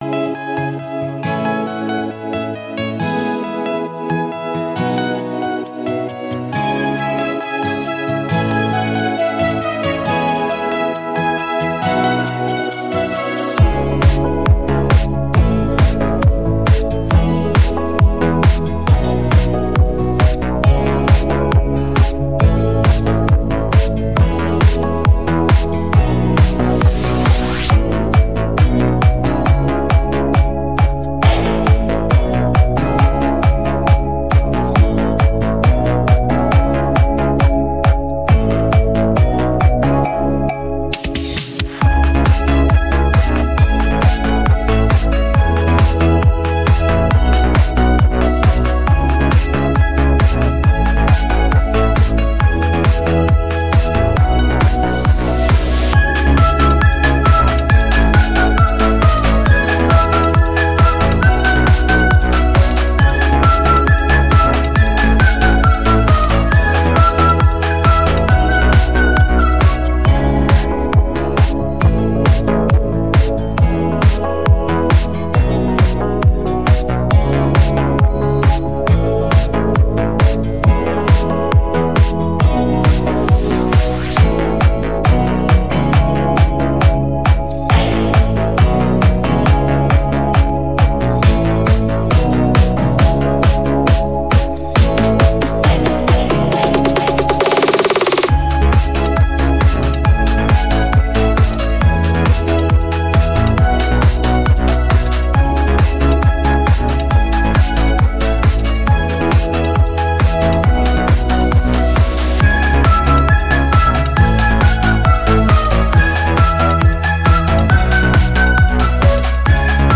детской песни